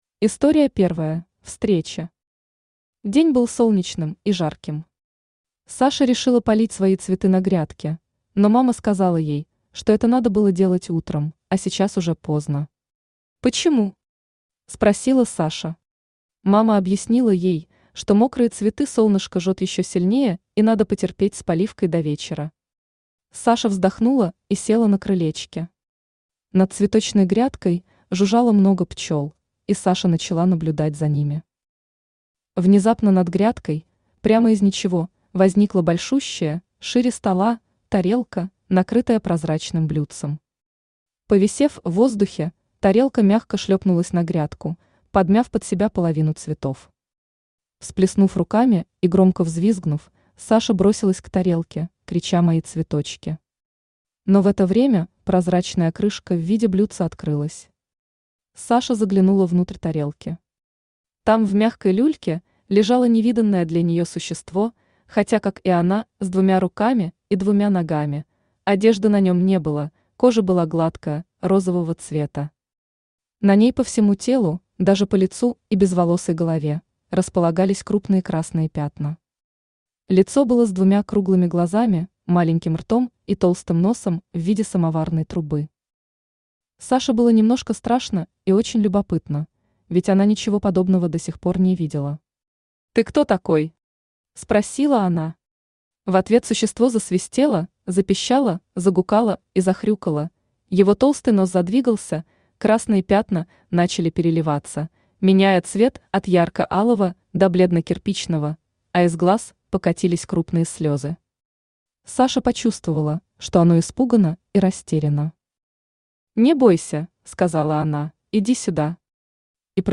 Аудиокнига Истории про Сашу и Орбика | Библиотека аудиокниг
Aудиокнига Истории про Сашу и Орбика Автор Лев Александрович Савров Читает аудиокнигу Авточтец ЛитРес.